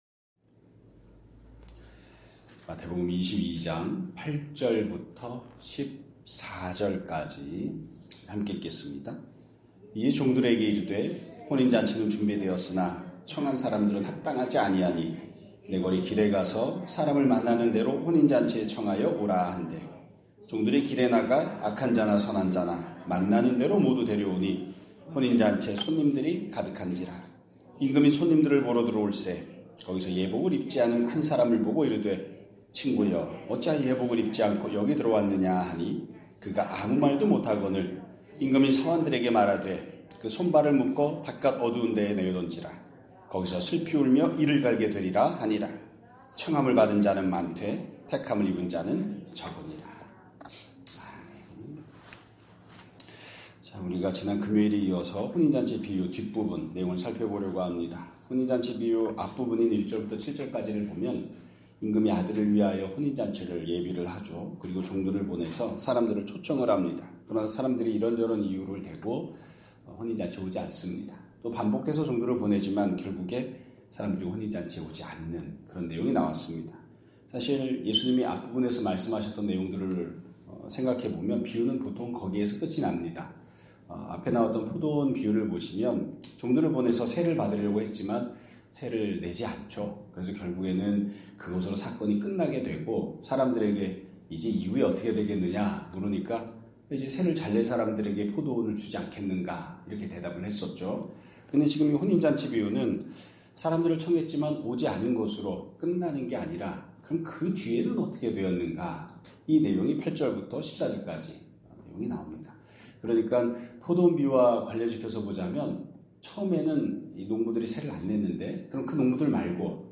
2026년 2월 9일 (월요일) <아침예배> 설교입니다.